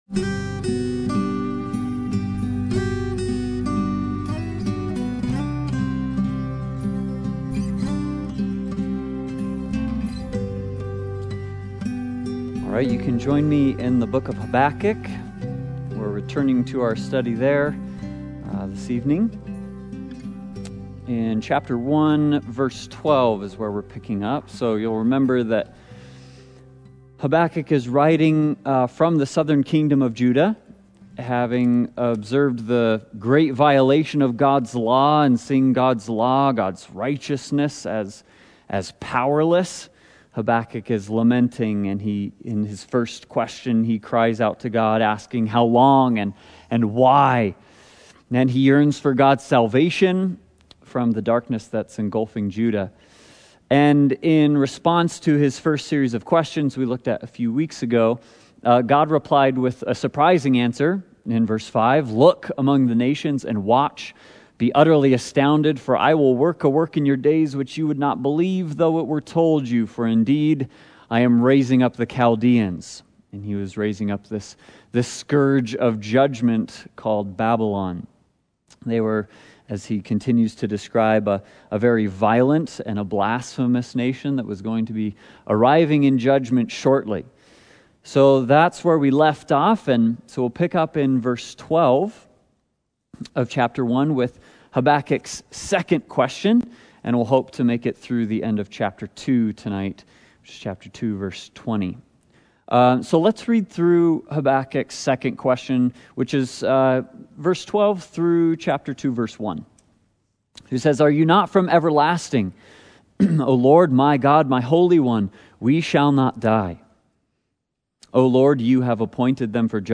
Passage: Habakkuk Service Type: Sunday Bible Study